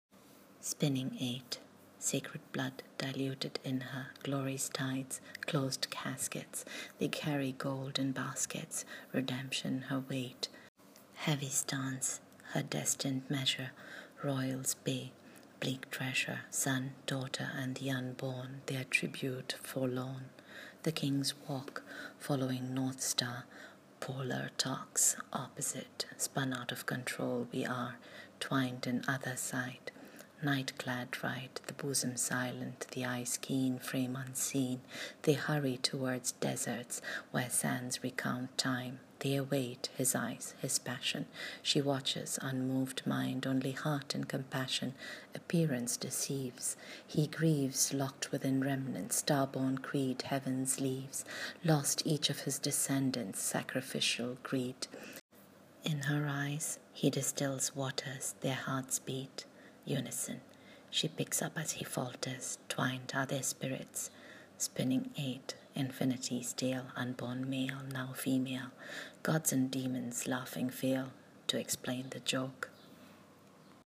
Reading of the poem: